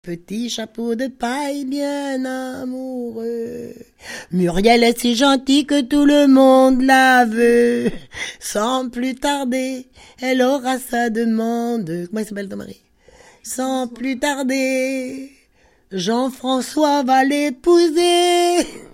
Note pour jouer à la corde
enfantine : lettrée d'école
Pièce musicale inédite